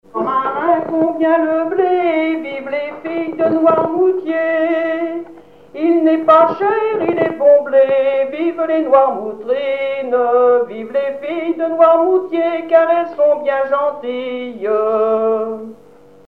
danse : branle
Genre laisse
répertoire de chansons traditionnelles